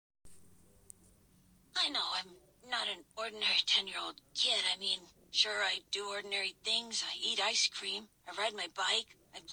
راوی فصل 1تا 6 مثلا همون نقش اصلی داستانه که یه پسرس که خب چهره متفاوتی داره از بقیه و صداش اینطوریه :point_down: